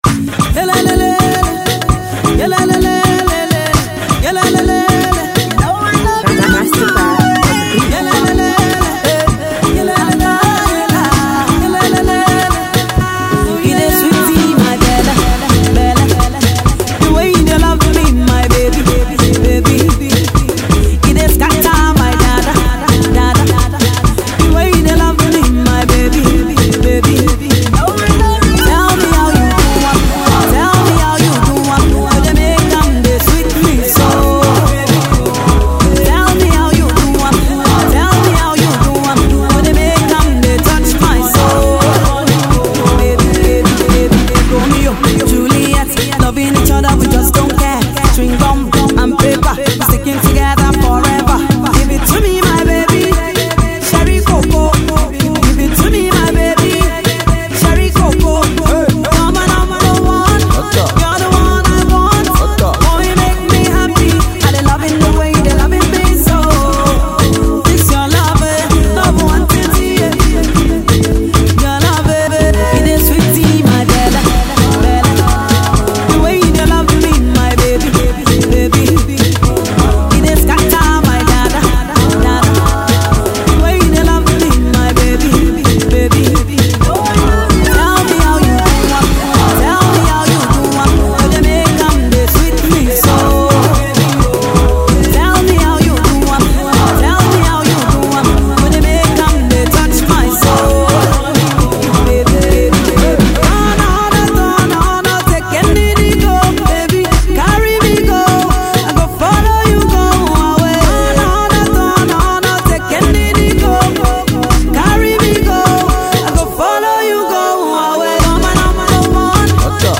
Pop
One of Nigeria’s deluxe female singer
a powerful love jam fueled with sweet melodious rhymes